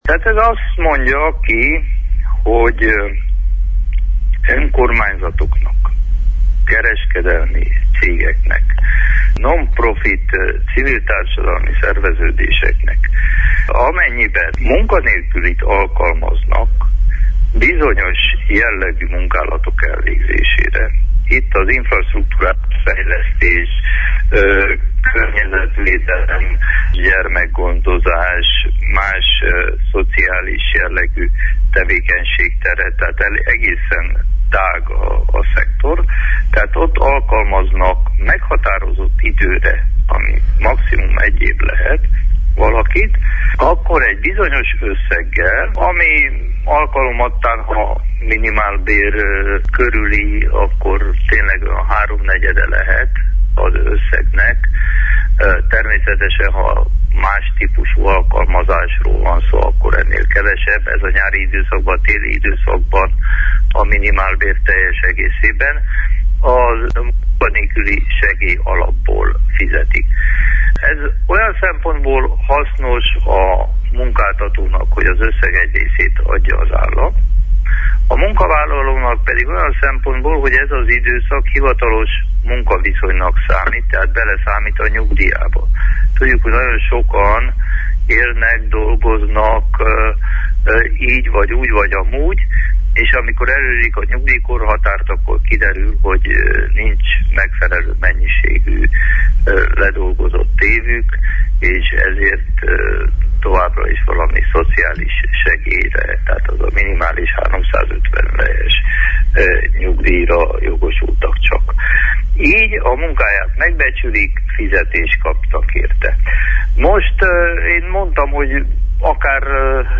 Ezen kezdeményezéséről Márton Árpád a következőket nyilatkozta a hétfő délutáni Naprakész műsorban :